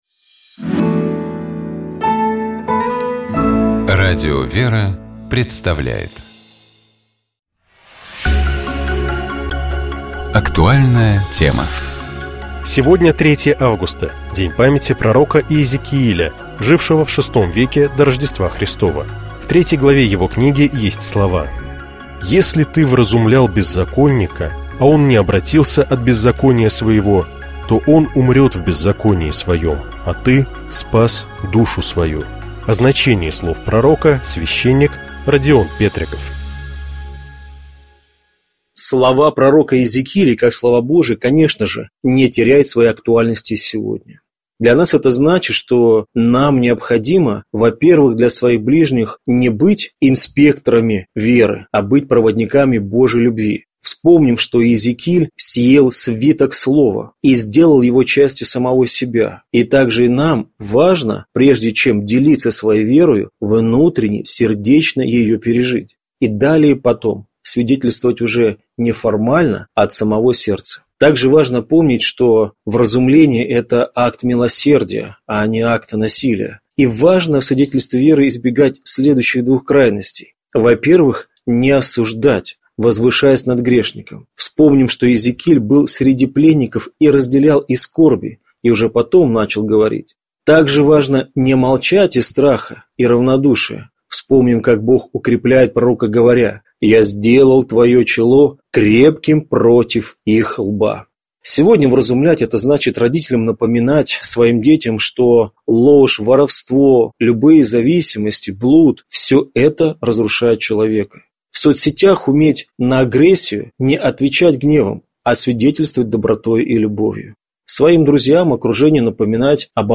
О значении слов пророка — священник